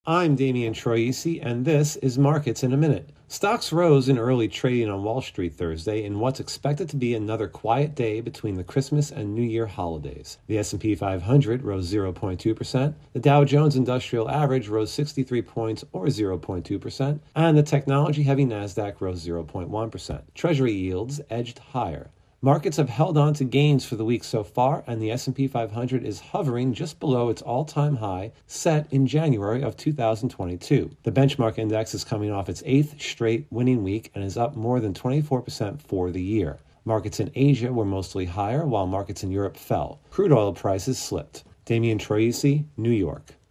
reports on Markets in a Minute - with stocks opening higher.